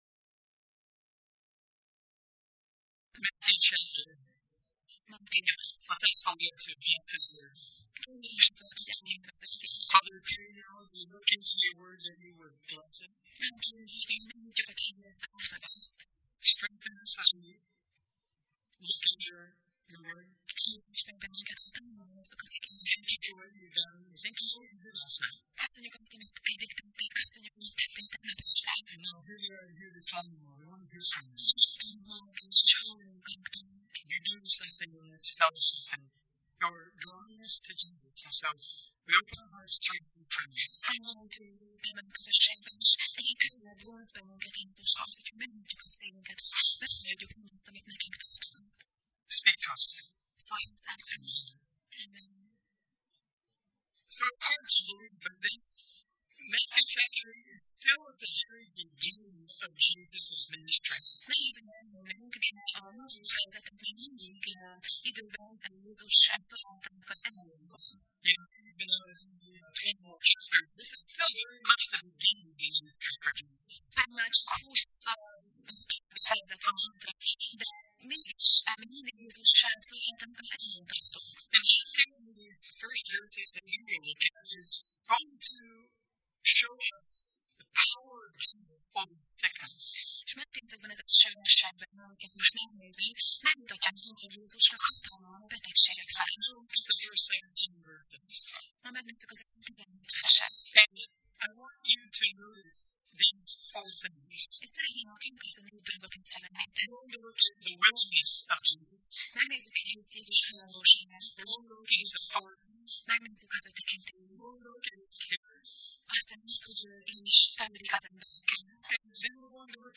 Máté Passage: Máté (Matthew) 8:1–17 Alkalom: Vasárnap Reggel